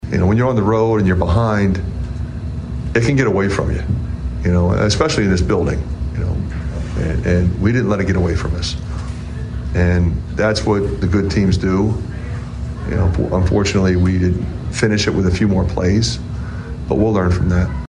That’s Iowa coach Fran McCaffery.